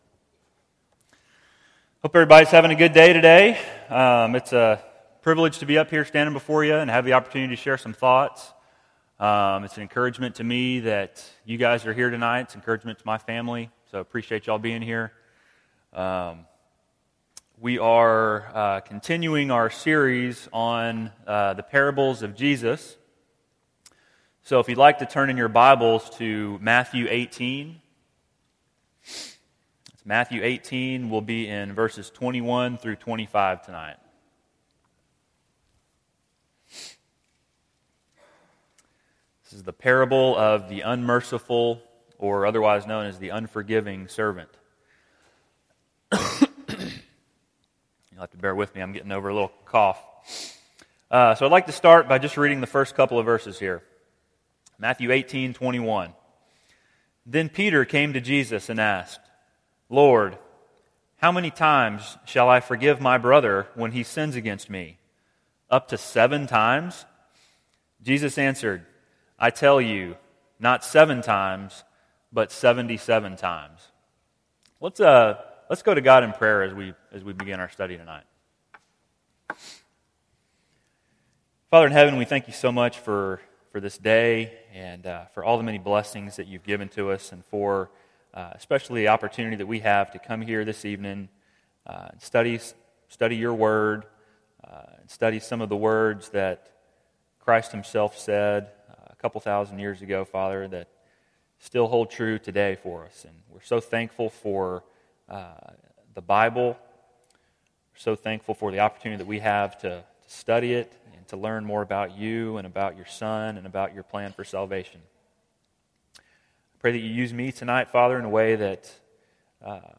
Topic: Parables Service Type: Bible Class